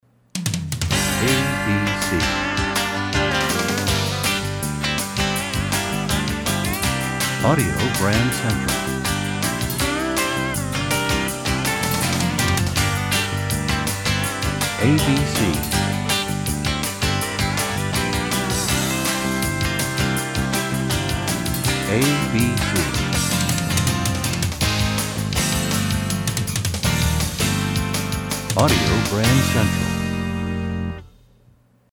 Genre: Jingles.